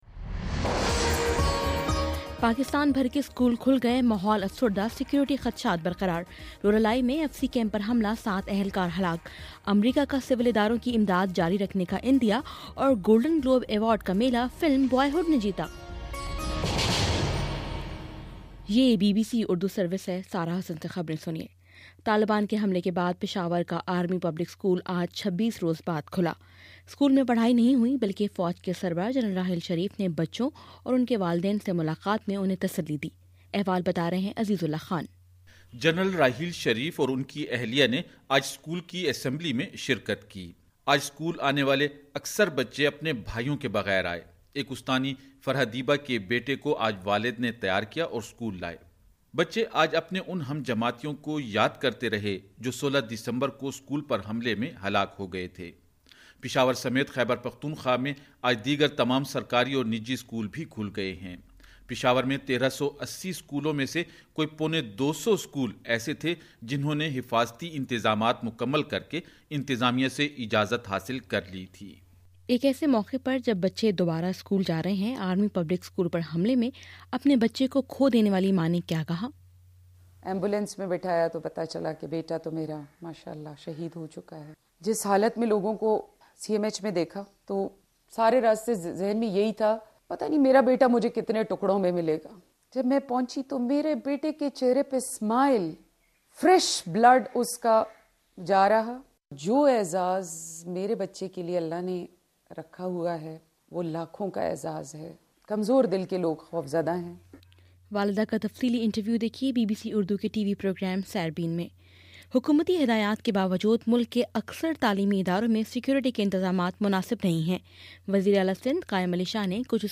جنوری 12: شام سات بجے کا نیوز بُلیٹن